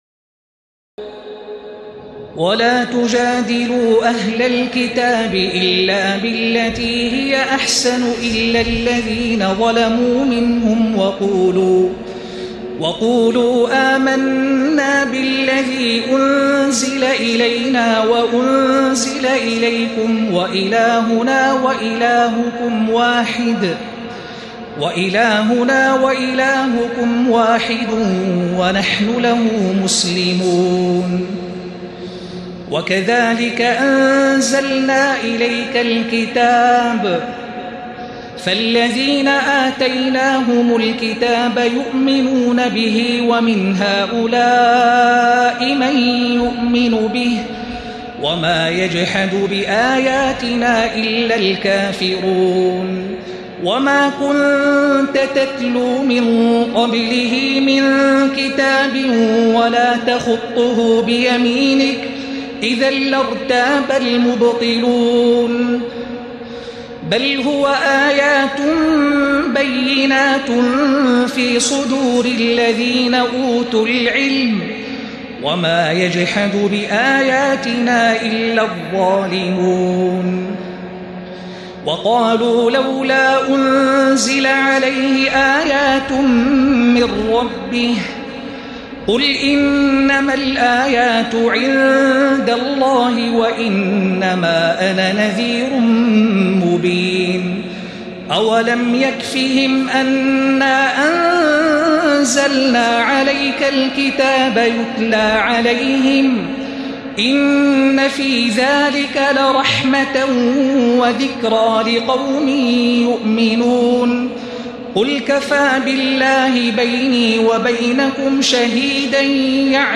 تراويح الليلة العشرون رمضان 1436هـ من سور العنكبوت (46-69) و الروم و لقمان (1-21) Taraweeh 20 st night Ramadan 1436H from Surah Al-Ankaboot and Ar-Room and Luqman > تراويح الحرم المكي عام 1436 🕋 > التراويح - تلاوات الحرمين